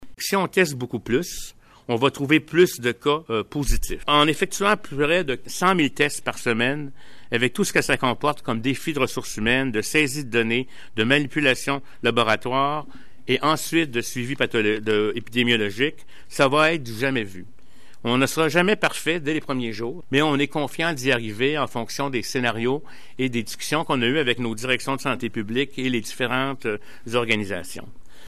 Le directeur national de la santé publique, le docteur Horacio Arruda, qui n’était pas accompagné du premier ministre François Legault pour la mise à jour quotidienne ce vendredi, s’est montré optimiste d’atteindre cet objectif.
CUT_ARRUDA_DÉPISTAGE.mp3